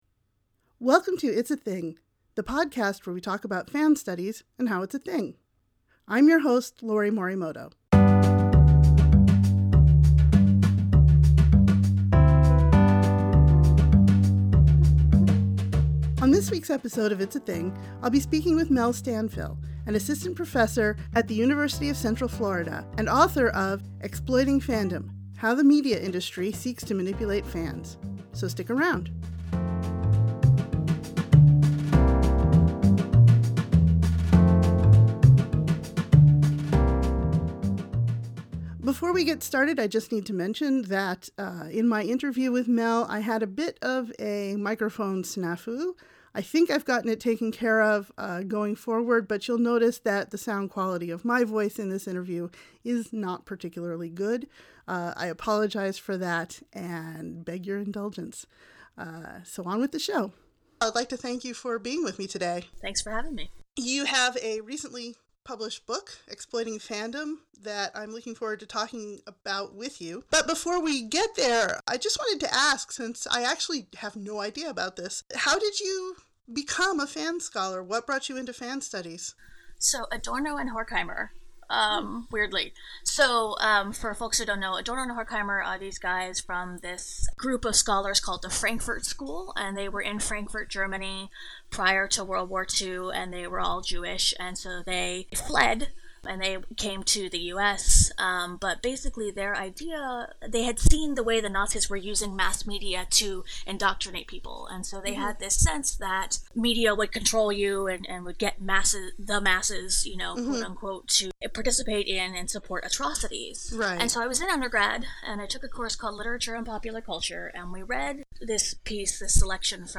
I think I’ve gotten it taken care of going forward, but you’ll notice that the sound quality of my voice in this interview is not particularly good.